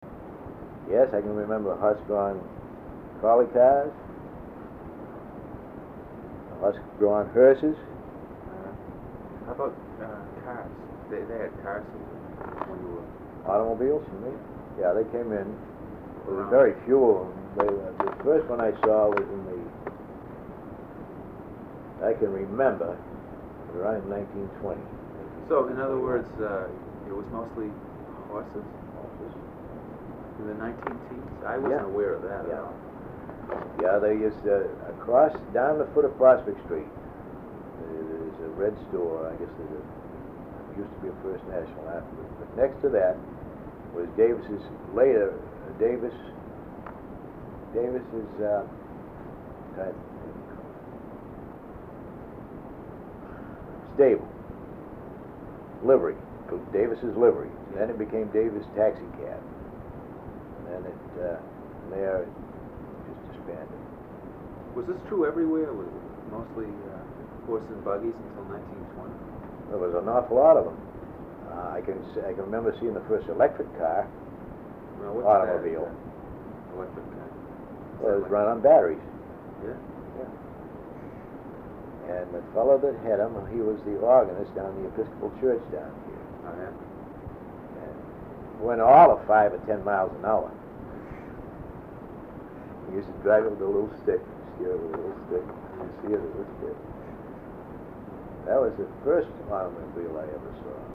Oral History Overview
In 2003, most of the original sound cassettes were converted to compact discs by the Sawyer Free Library.